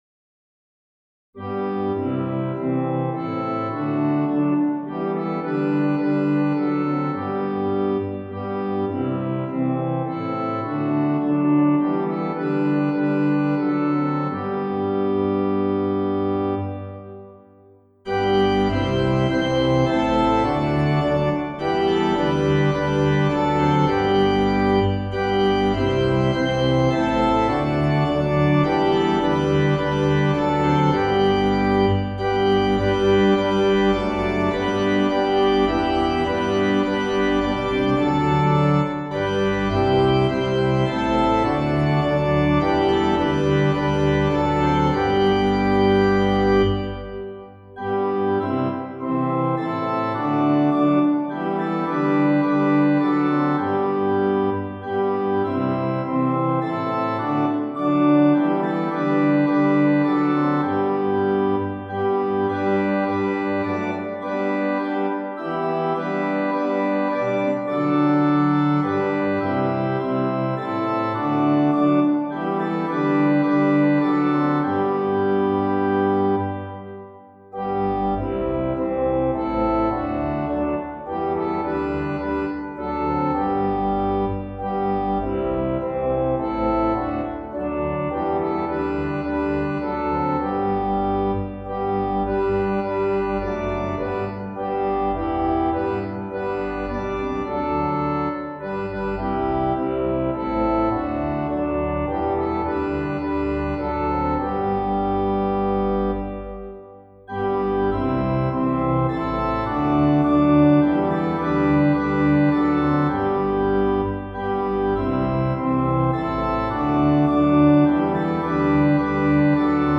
Traditional Welsh